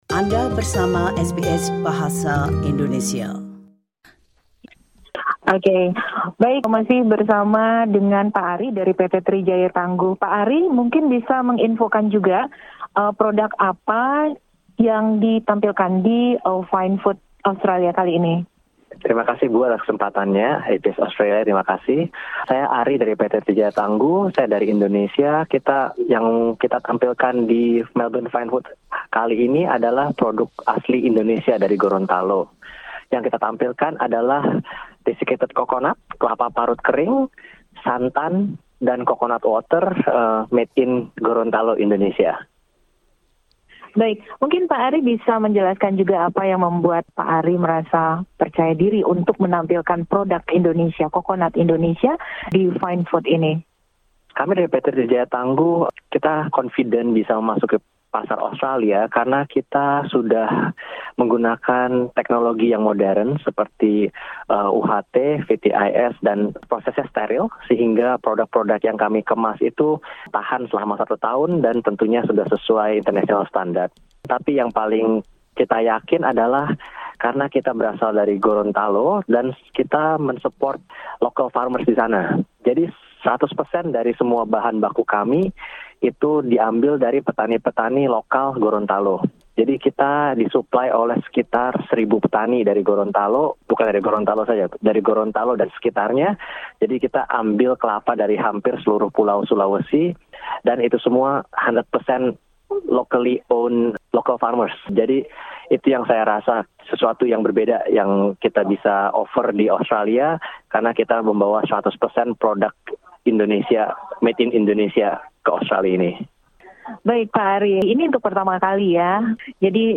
Indonesian Trade Promotion Centre at Fine Food Australia Expo 2022